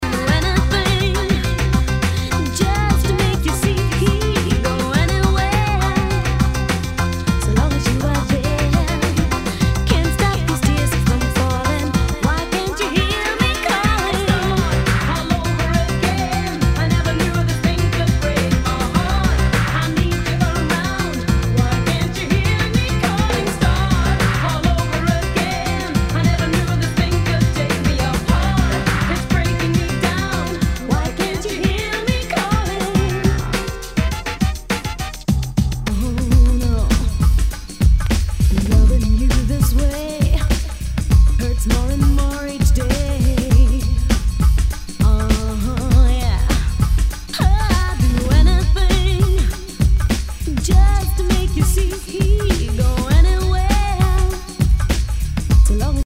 Nu- Jazz/BREAK BEATS
ナイス！ダウンテンポ・クラシック！